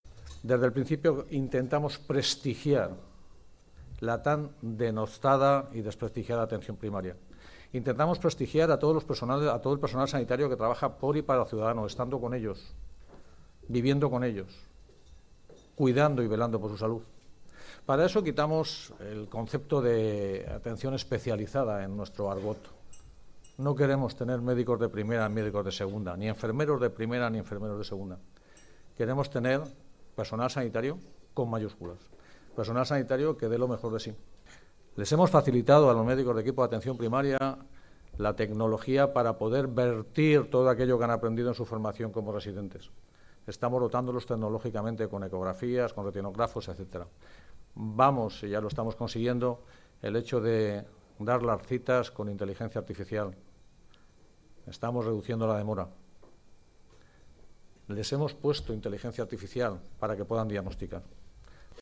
Así se ha pronunciado durante su intervención en un desayuno informativo de Fórum Europa Tribuna Mediterránea.